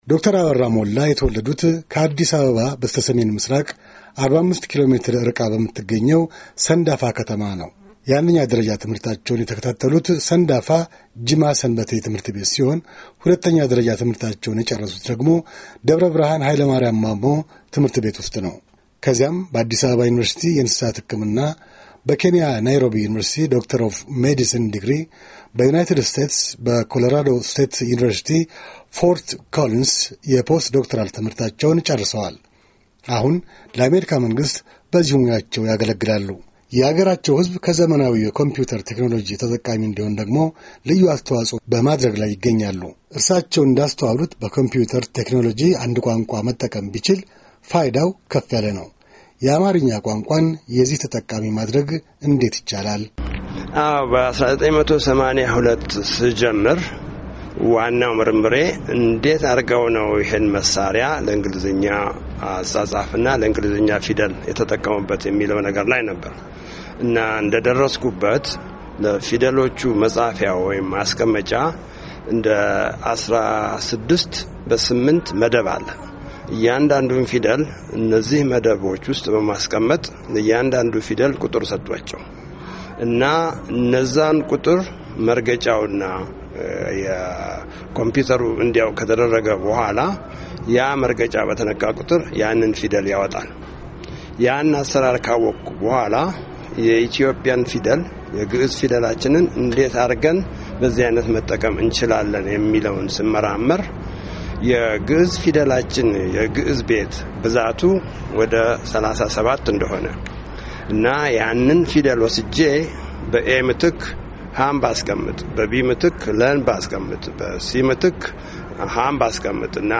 በኮምፒተር ቴክኖሎጂ ለአማርኛ አጻጻፍ ያደረጉትን አስተዋጽኦ በተመለከተ በቪ.ኦ.ኤ. ቃለ መጠይቅ ተደርጎላቸው ነበር፡፡